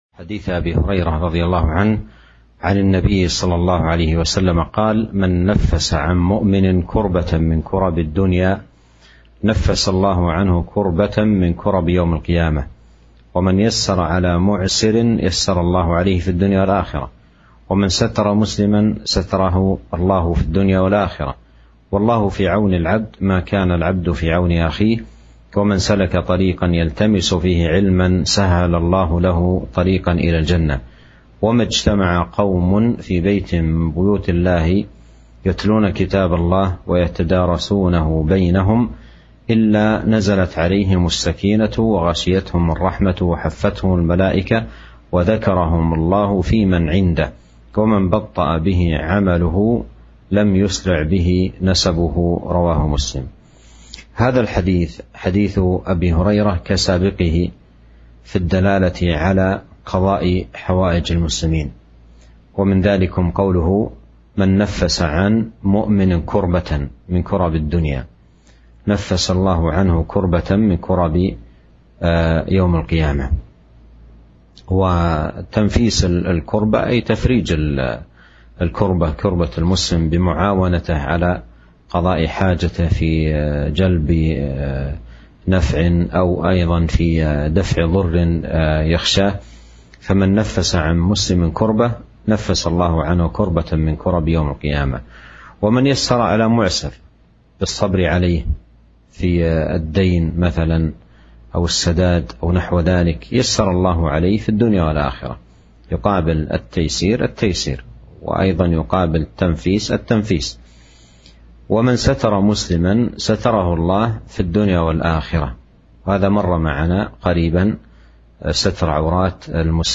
شرح حديث والله في عون العبد ما كان العبد في عون أخيه